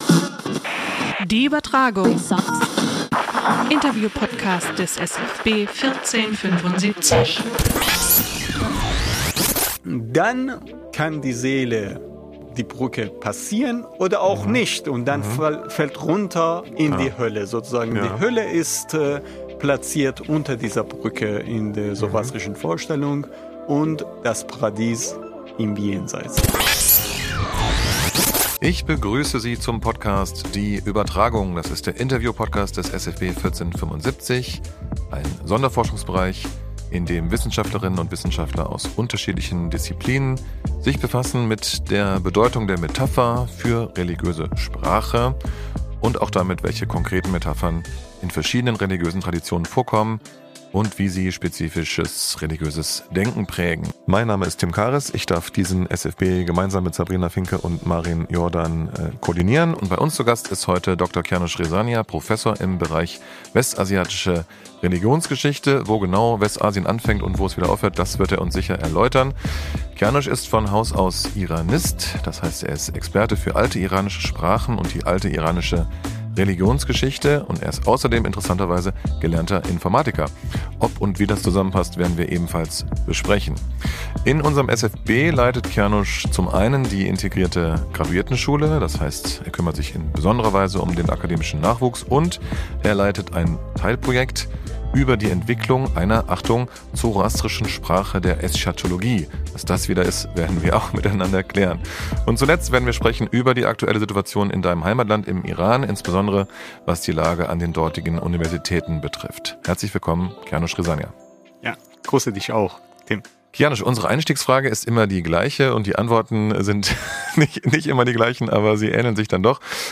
Die Übertragung: Interview-Podcast des SFB 1475